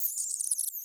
Mouse Squeak Sound
animal
Mouse Squeak